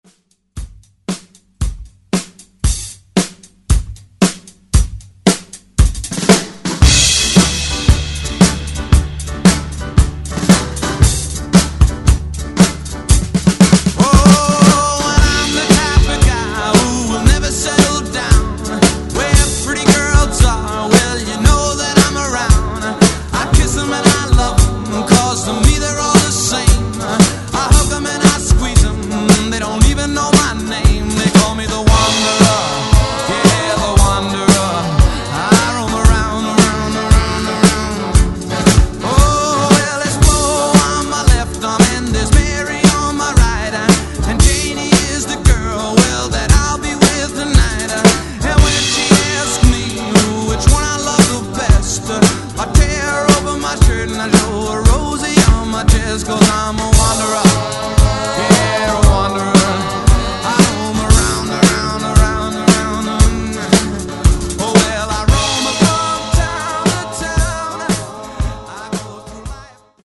Genre: 2000's Version: Clean BPM: 130 Time